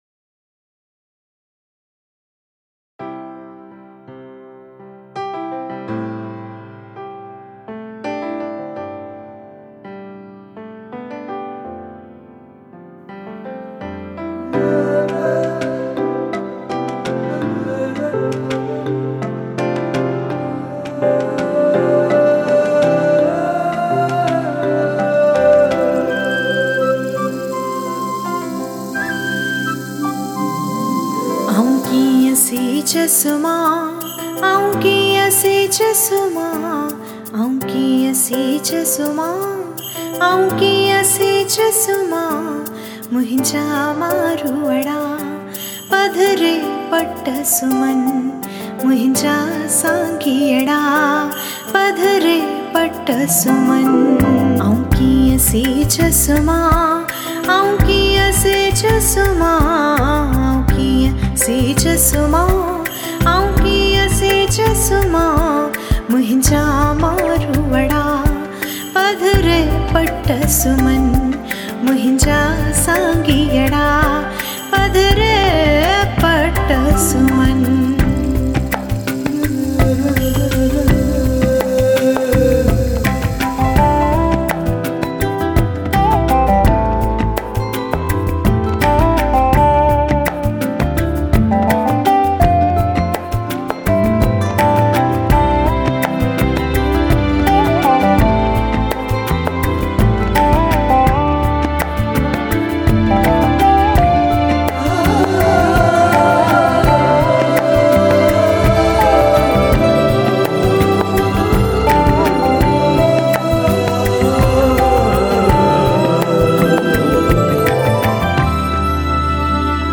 Collection Melodious Sindhi SOngs